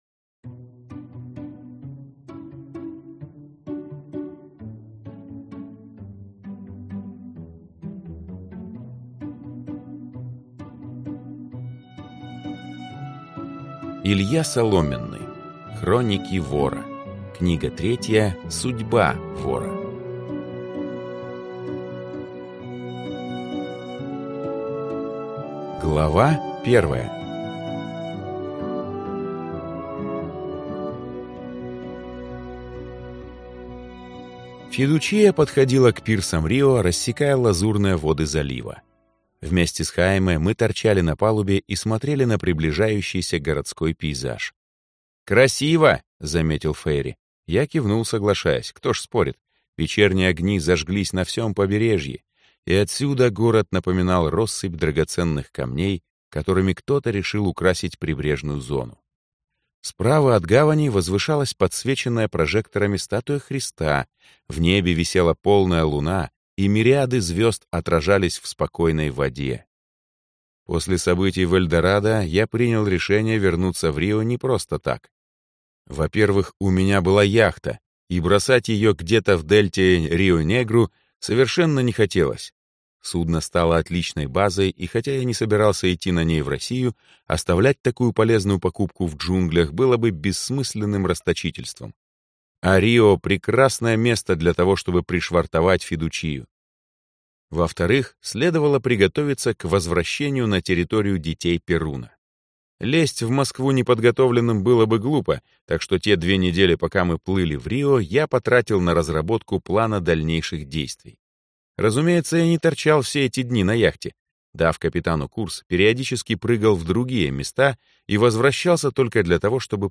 Аудиокнига Судьба вора | Библиотека аудиокниг